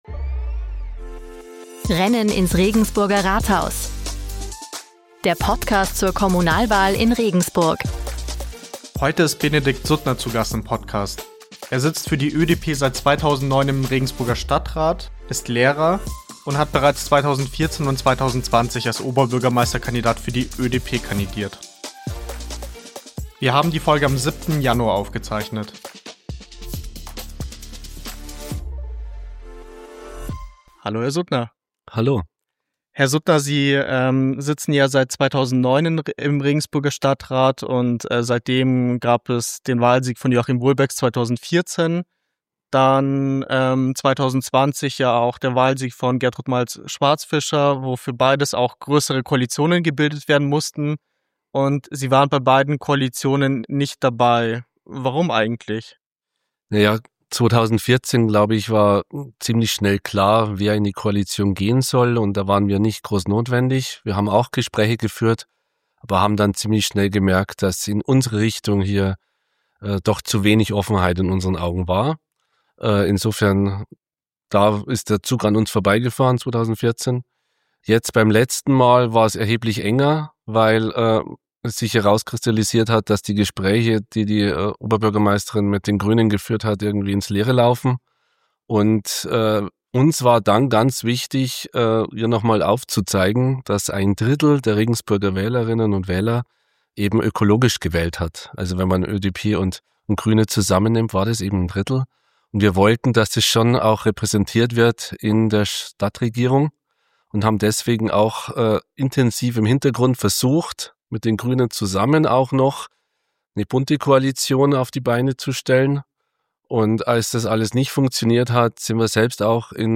Ein Gespräch über Mut, ehrliche Kompromisse und die Zukunft Regensburgs im Jahr 2026.